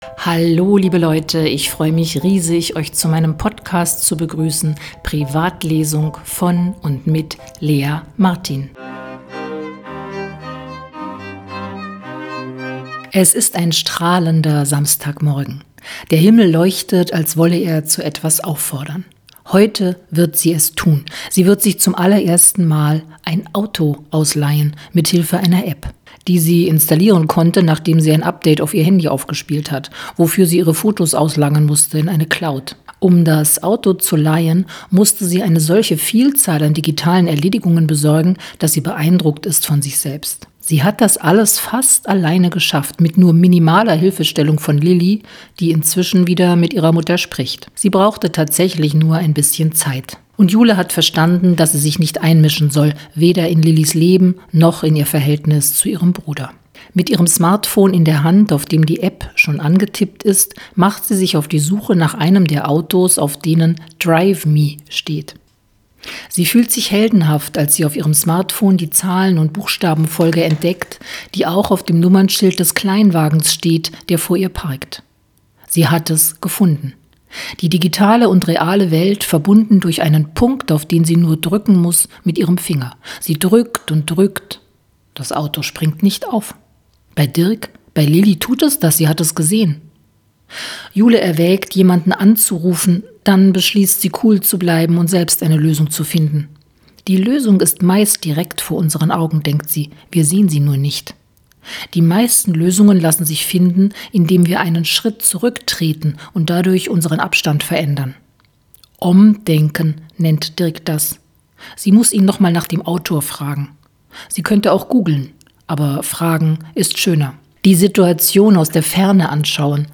Die Entstehung der Liebe: Roadtrip durch Berlin ~ Privatlesung Podcast